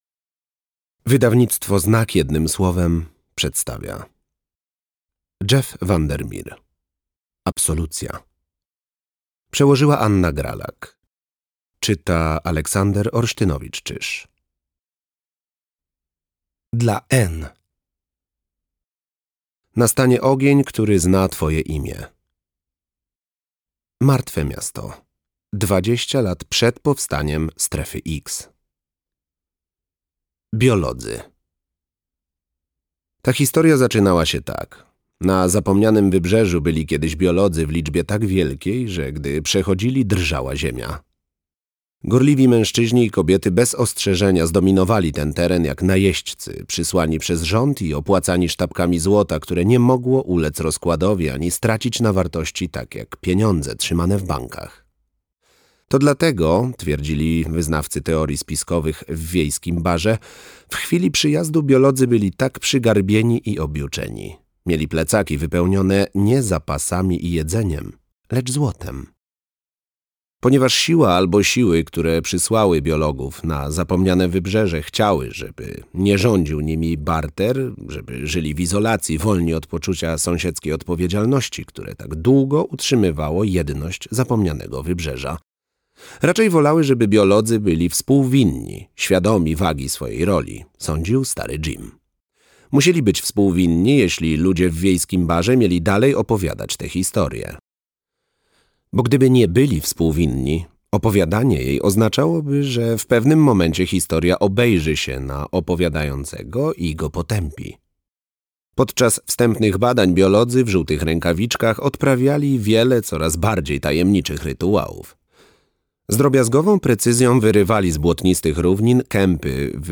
Audiobook Absolucja, Jeff VanderMeer.